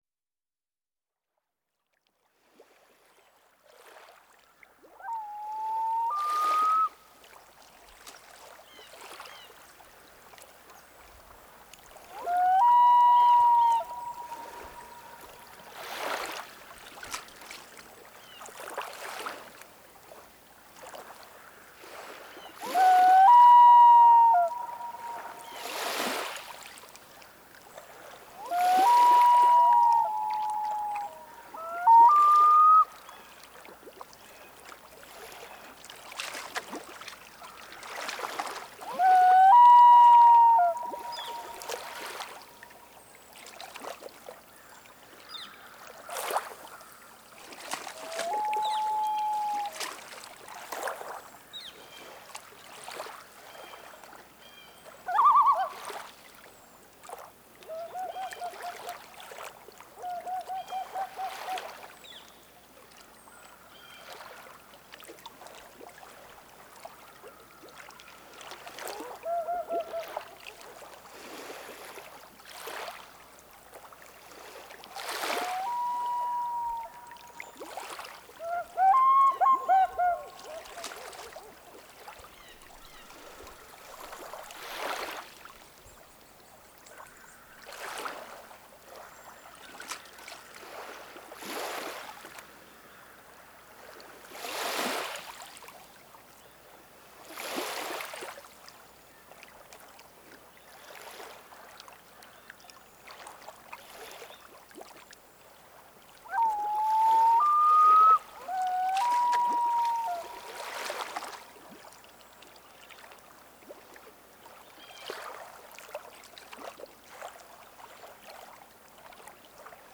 Loon
They are fun to watch as they paddle around a lake and suddenly dissappear as they dive for fish. In early summer you may find one with its babies on its back and their erie call sets the mood for a quiet morning on a lake.
01-dawn.m4a